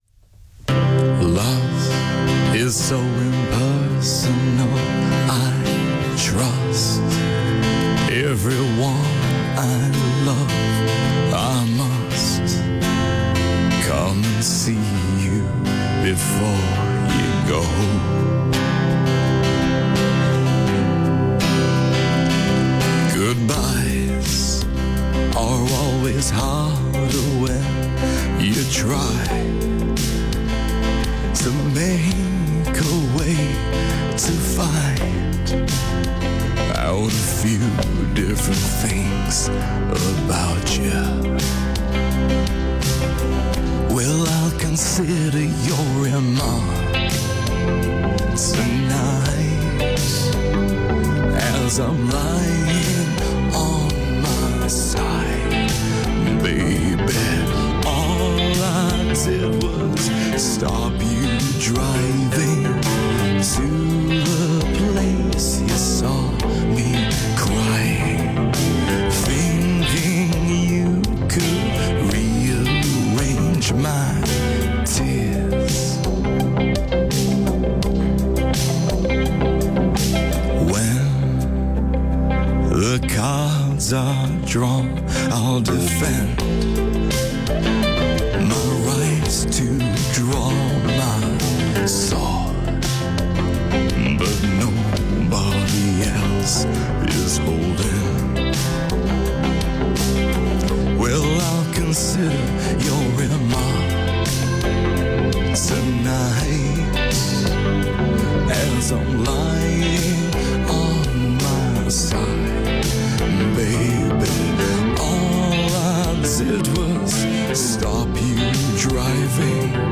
breezy, catchy and very 80s.